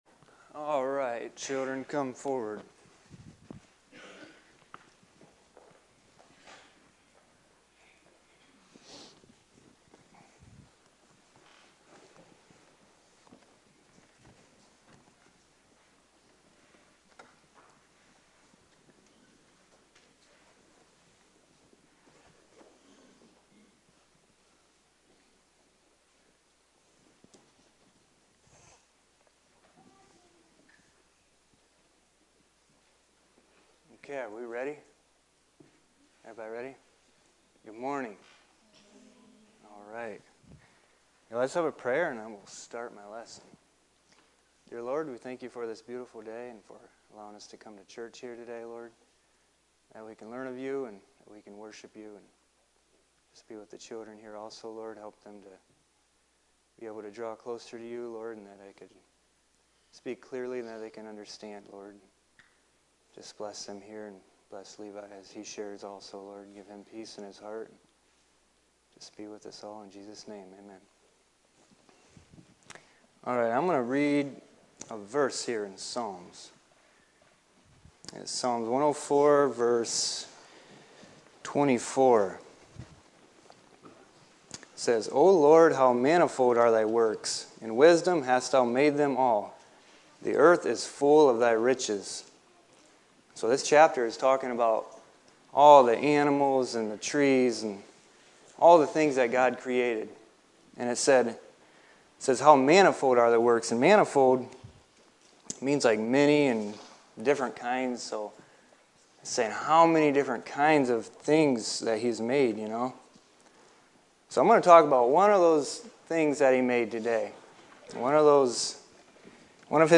Children's Lessons